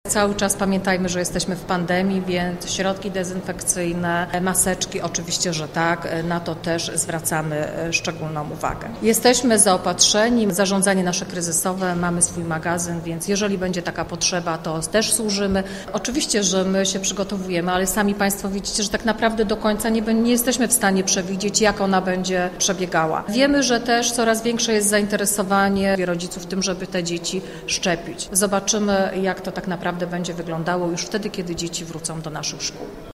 Wiceprezydent Domagała przypomina także, że będzie to kolejny rok nauki z pandemią w tle.